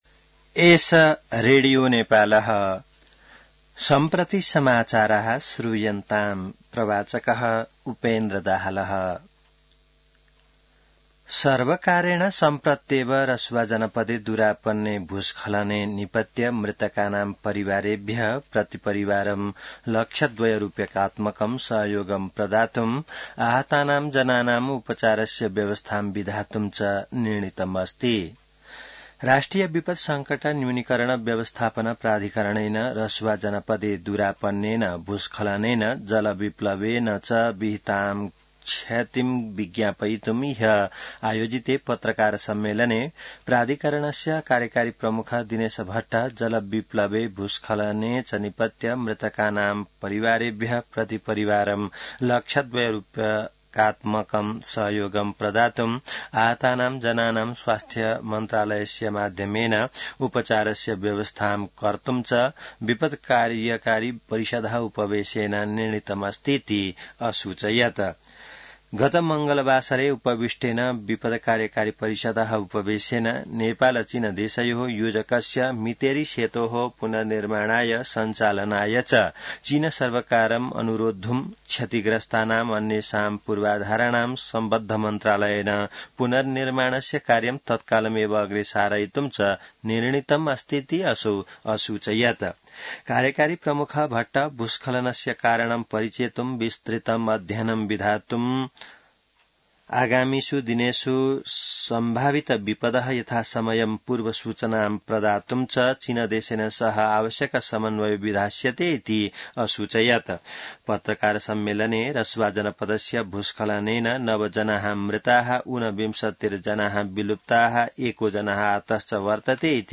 संस्कृत समाचार : २६ असार , २०८२